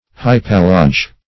Hypallage \Hy*pal"la*ge\, n. [L., fr. Gr. ?, prop., interchange,